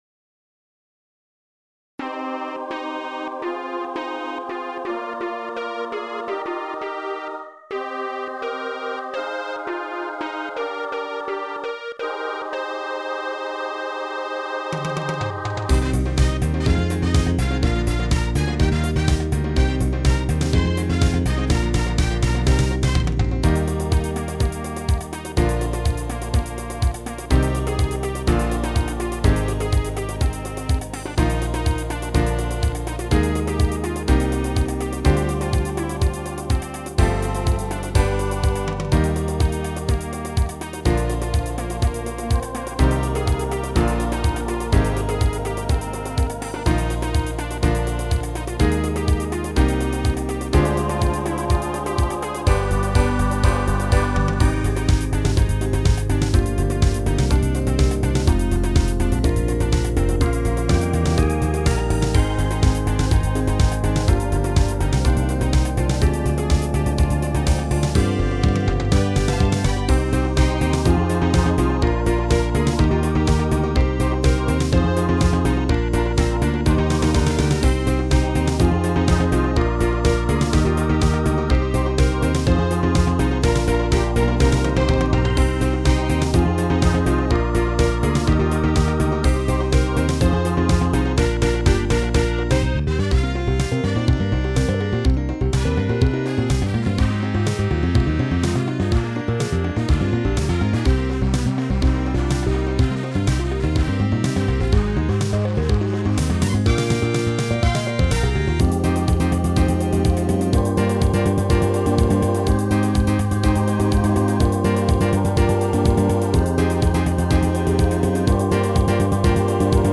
女性ボーカル版